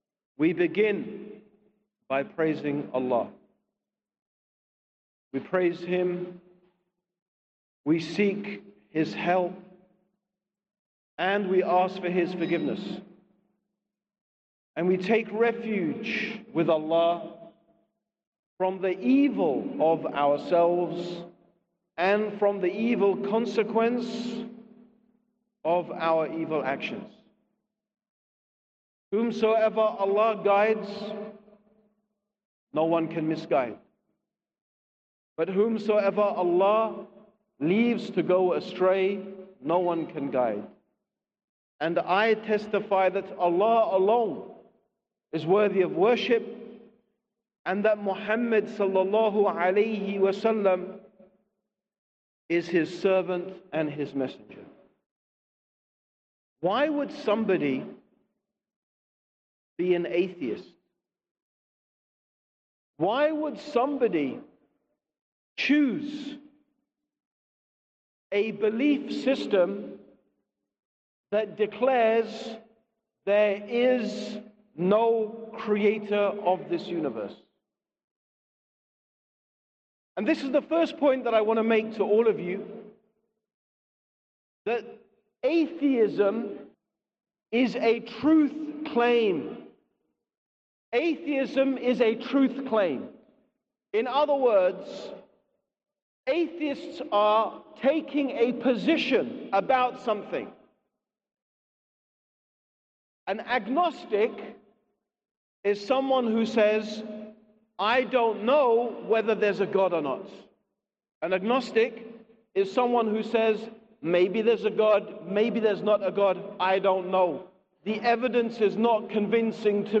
Lecture on Atheism